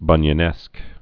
(bŭnyə-nĕsk)